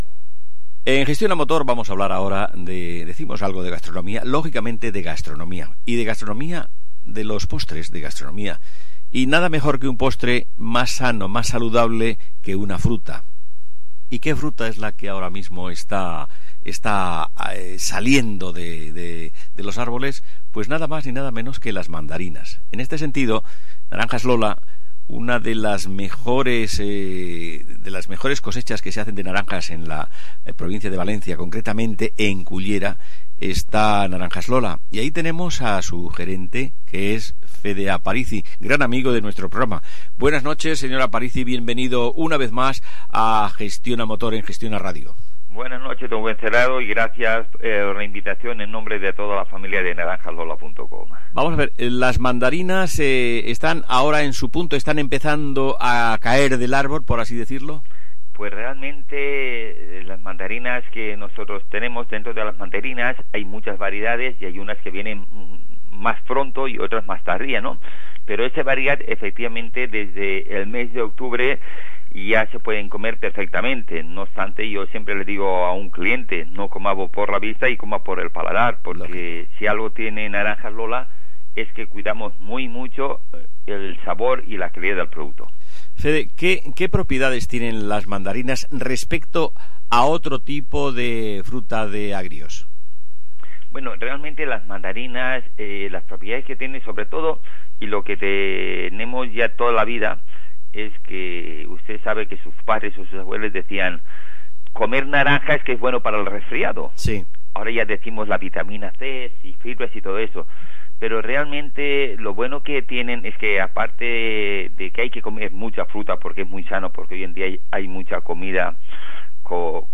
Entrevista en el programa Gestiona Motor de Gestiona Radio con motivo del inicio de la nueva temporada de cítricos.